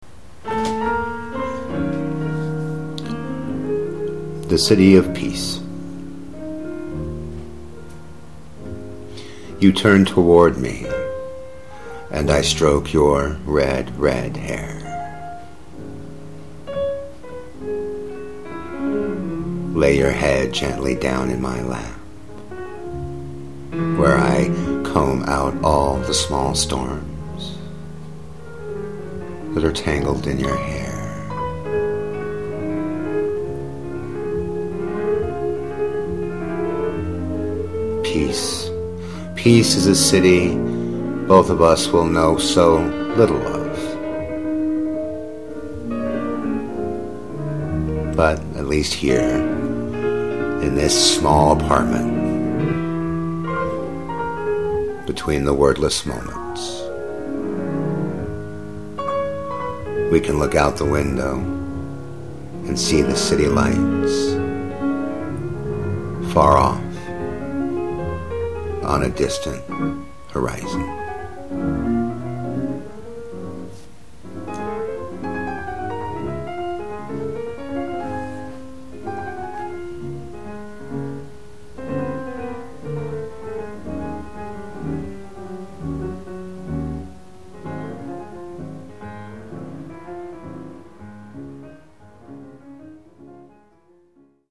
Poetry
Audio for the poem (slightly different version)